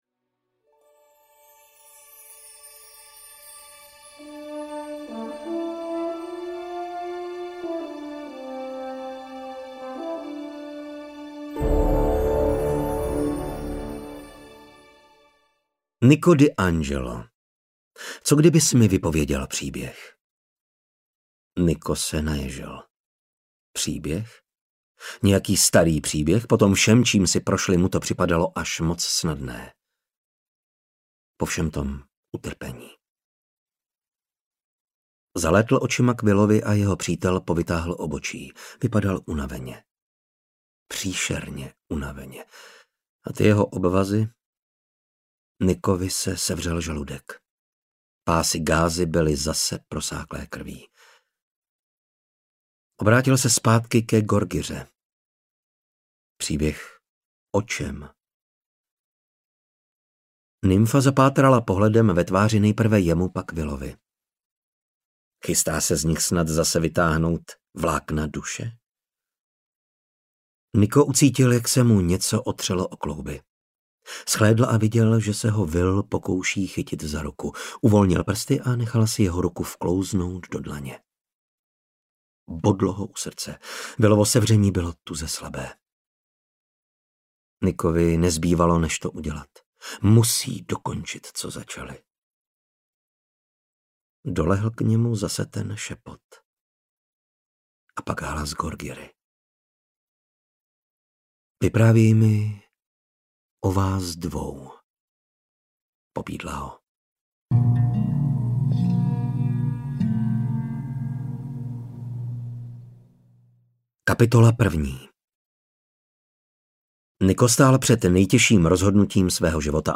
Slunce a hvězda audiokniha
Ukázka z knihy